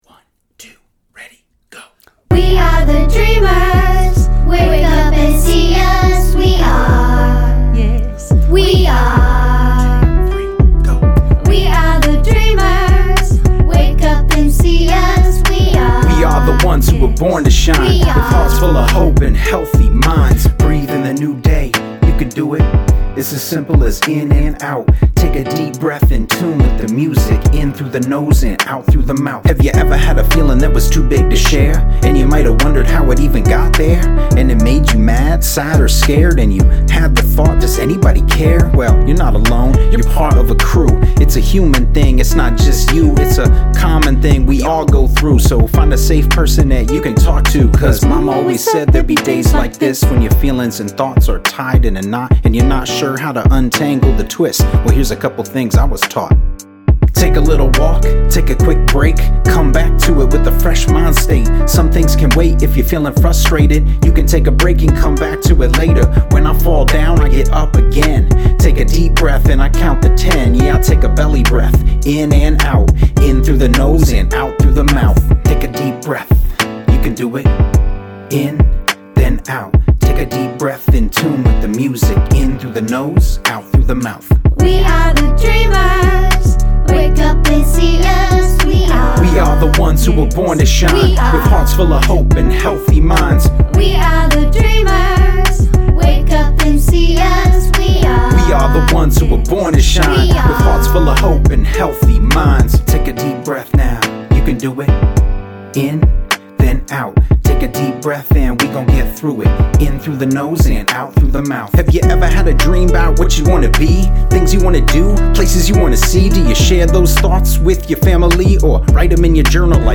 fun, upbeat song